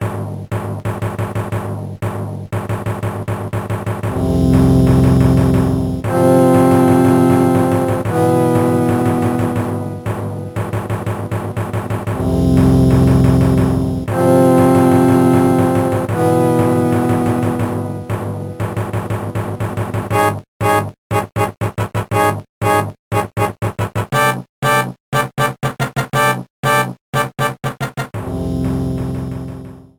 Trimmed and fade out